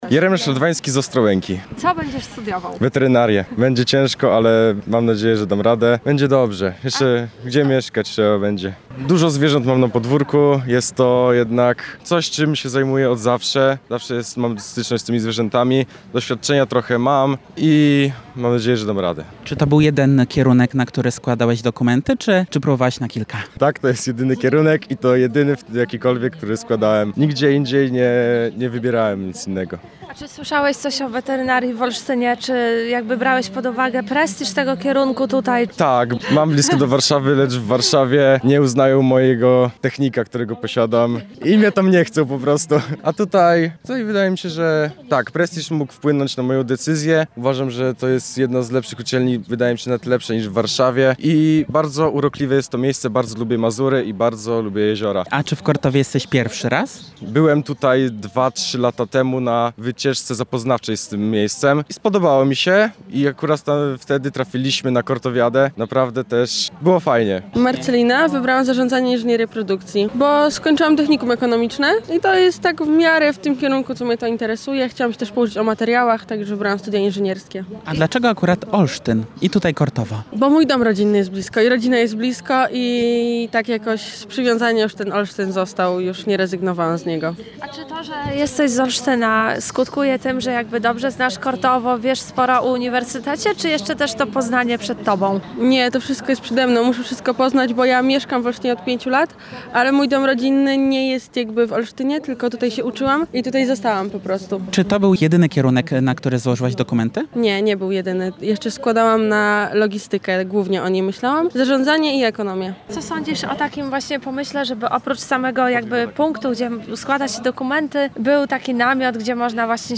Na koniec porozmawialiśmy z odwiedzającymi Bibliotekę Uniwersytecką i zapytaliśmy, jakie kierunki studiów wybrali i dlaczego.